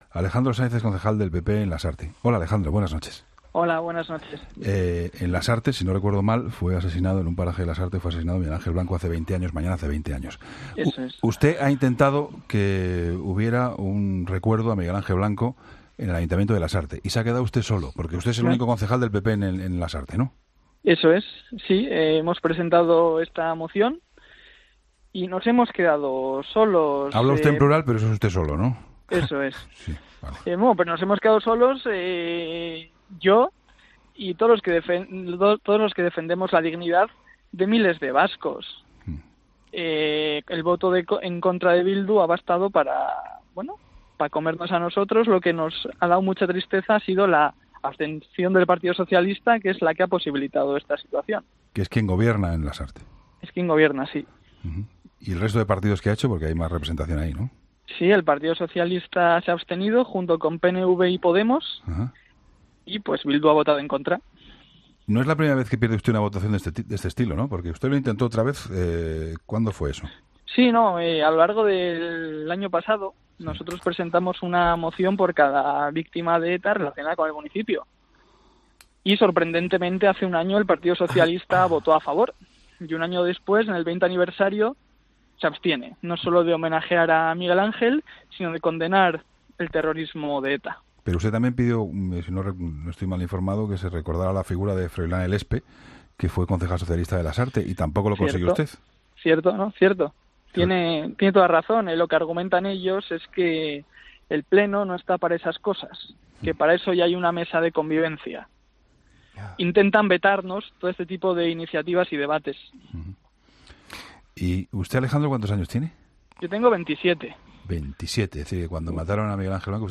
En 'La Linterna' ha asegurado el concejal del PP en el municipio, Alejandro Sáenz, que la moción presentada era por cada “víctima de ETA relacionada con la ciudad”.